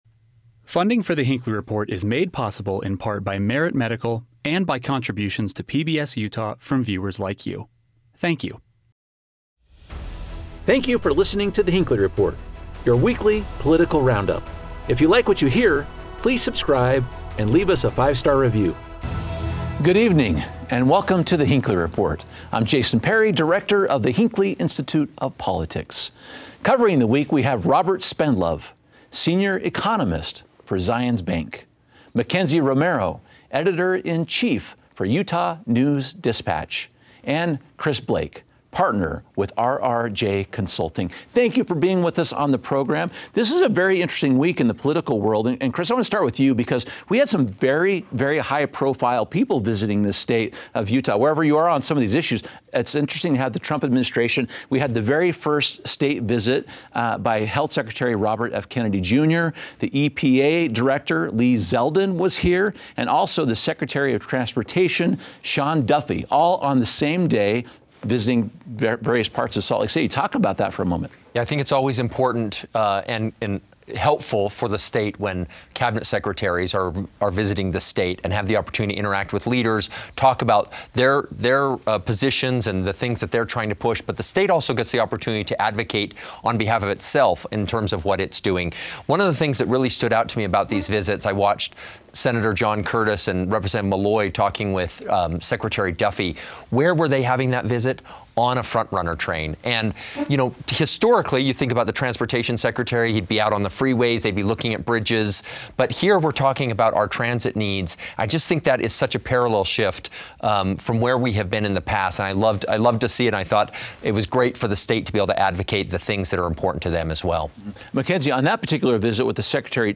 Our expert panel discusses why the three federal leaders have high praise for Utah. Kennedy specifically praised a bill recently passed by the Utah legislature that bans fluoride from water systems in the state.